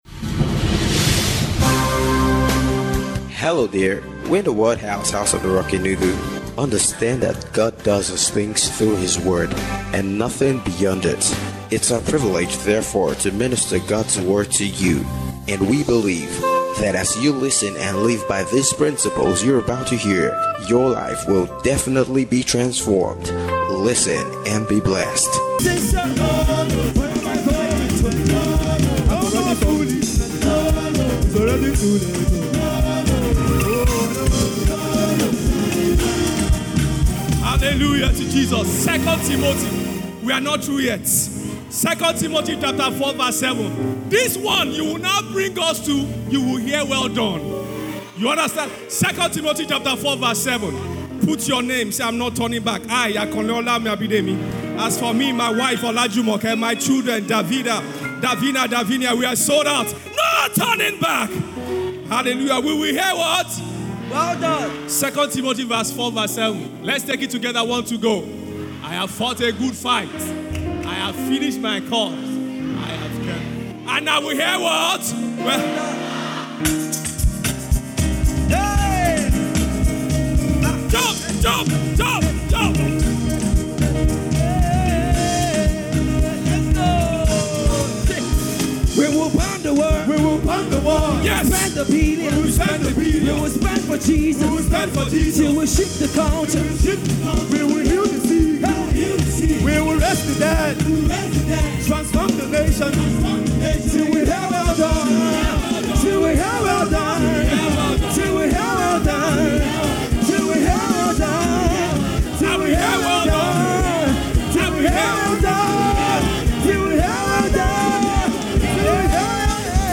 EMOTIONAL INTELLIGENCE (SUNRISE SERVICE)